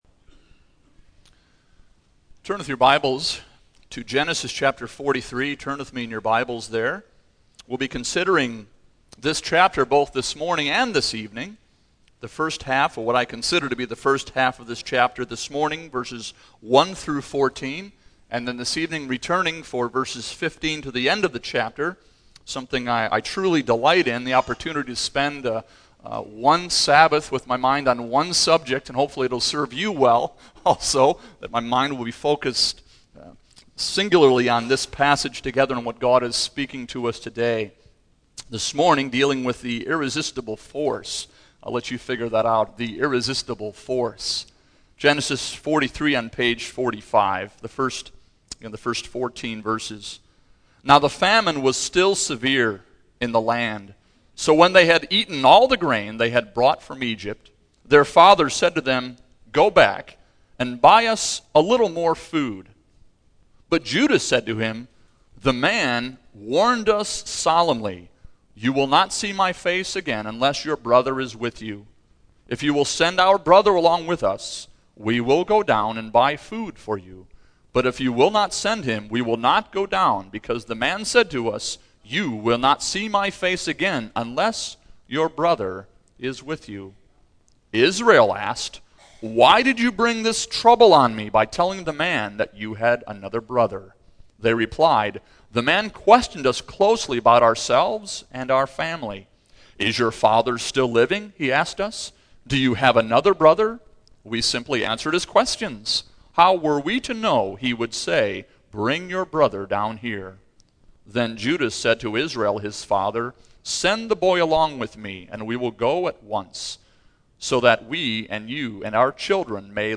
The Irresistible Force Preacher